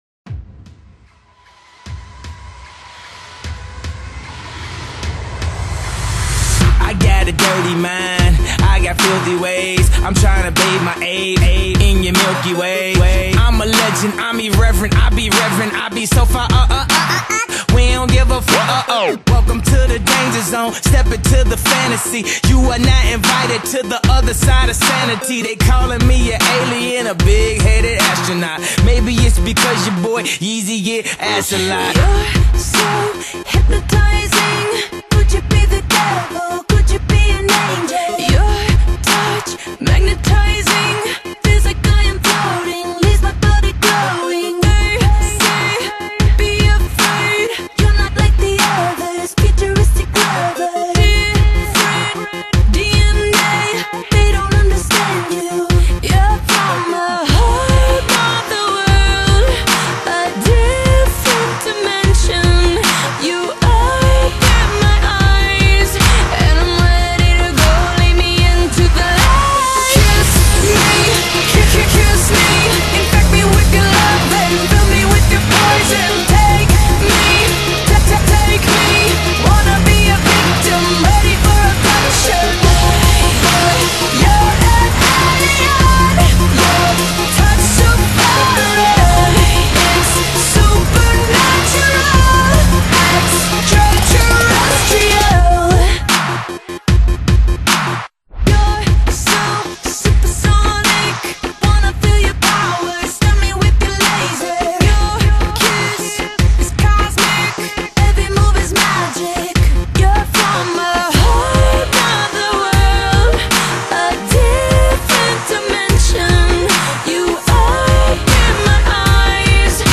Hip-Pop